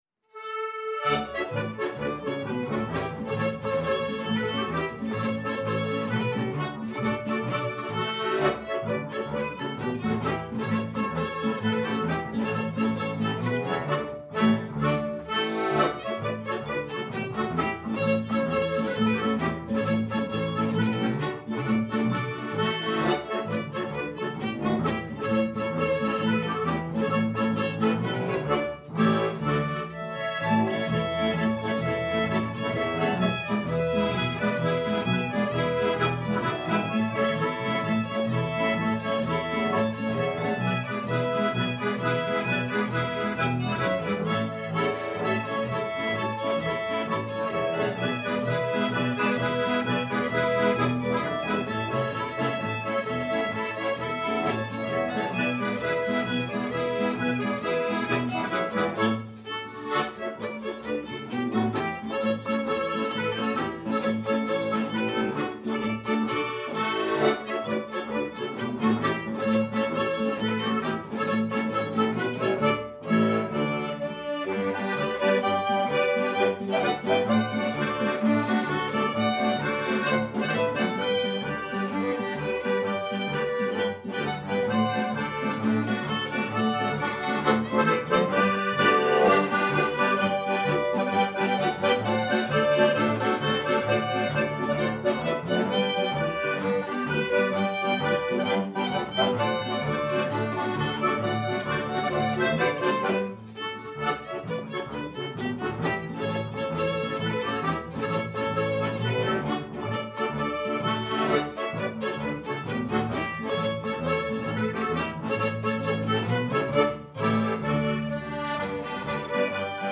Sarsteiner_Polka.mp3